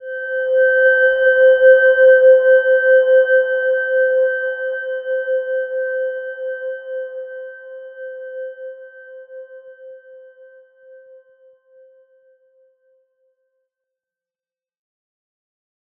Silver-Gem-C5-mf.wav